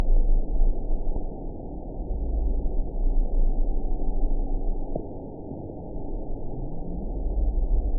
event 922705 date 03/15/25 time 06:10:59 GMT (1 month, 2 weeks ago) score 7.51 location TSS-AB10 detected by nrw target species NRW annotations +NRW Spectrogram: Frequency (kHz) vs. Time (s) audio not available .wav